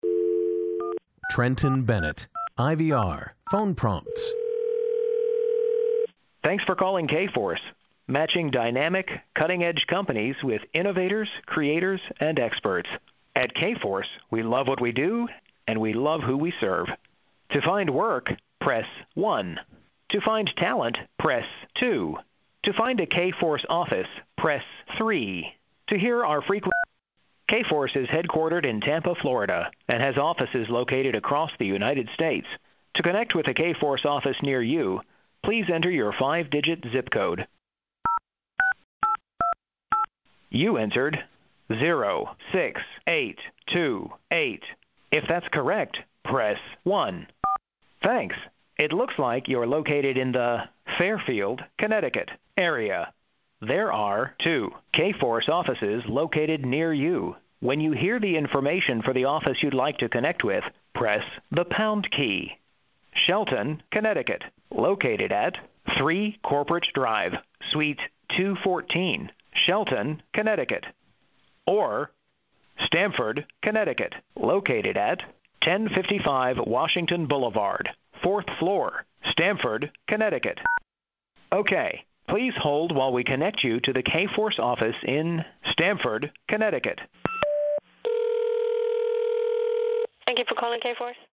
Animation/Video Games: IVR/Phone Prompts:
US: General American, Kentucky, Texas